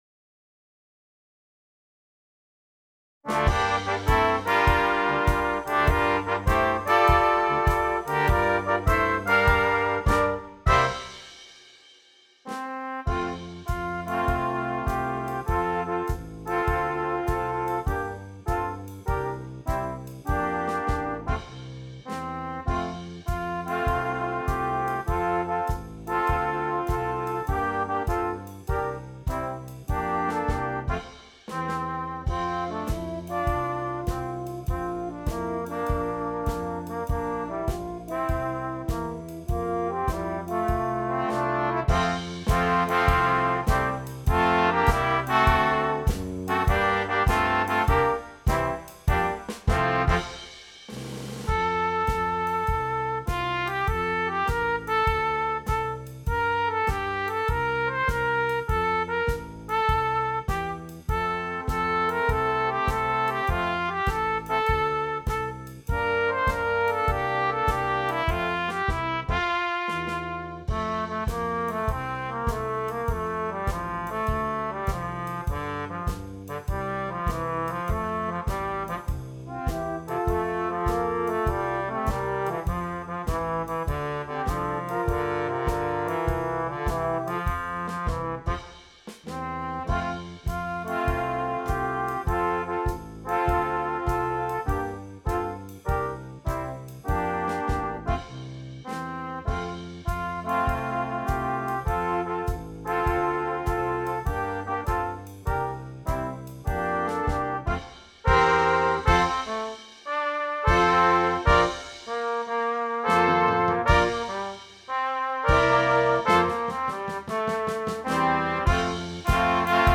Brass Quintet (optional Drum Set)
Traditional